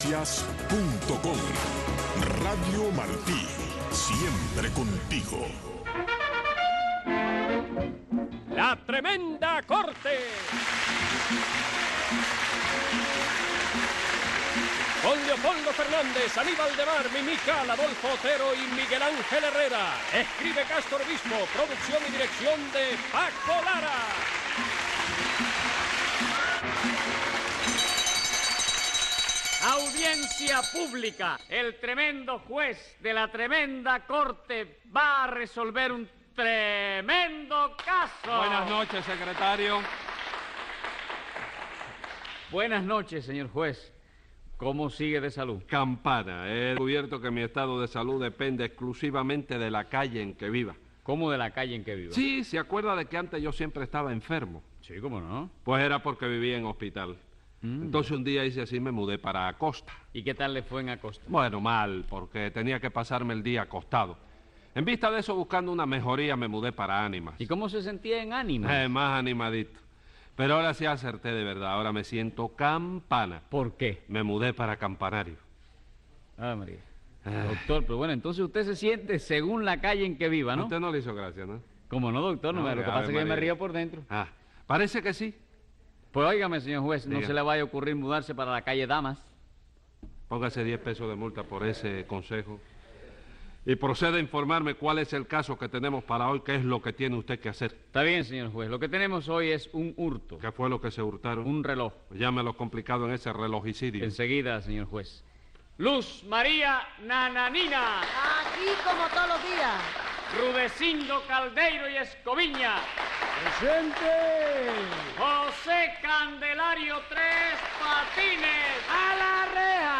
Divertidísimas situaciones en el más puro humor cubano en el legendario show de Tres Patines.